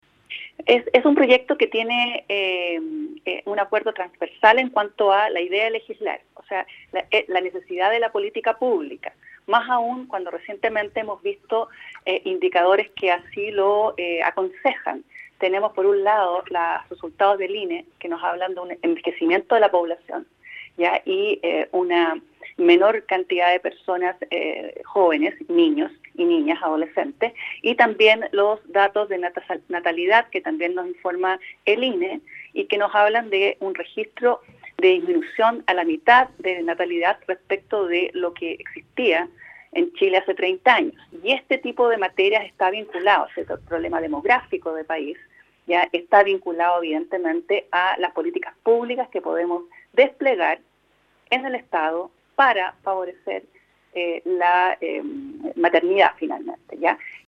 En entrevista con Nuestra Pauta, la subsecretaria de la Mujer y la Equidad de Género, Claudia Donaire Gaete, señaló que el proyecto cuenta con apoyo transversal para aprobar la idea de legislar, sobre todo tras conocer los resultados del último Censo, que muestra cifras de envejecimiento y una menor tasa de natalidad en el país.